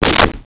Whip 06
Whip 06.wav